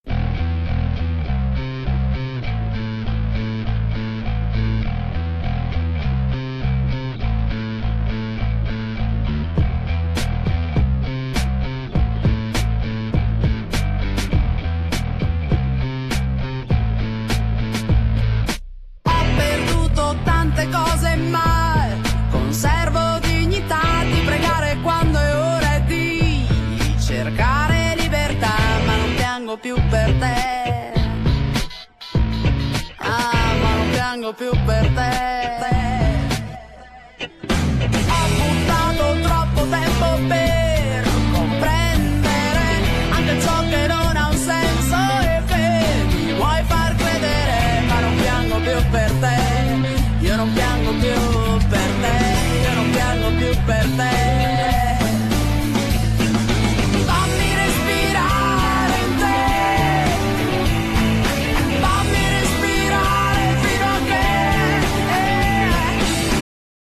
Genere : Pop
rock-pop band
Il suono rock, l’occhiolino al pop